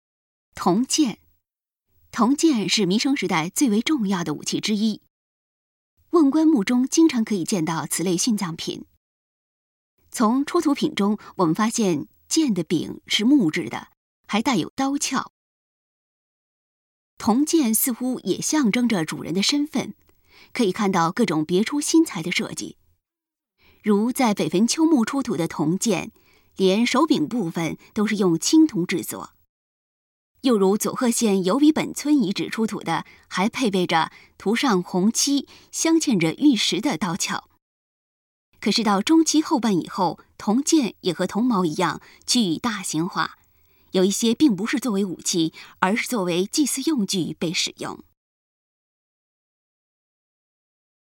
语音导览 前一页 返回手机导游首页 (C)YOSHINOGARI HISTORICAL PARK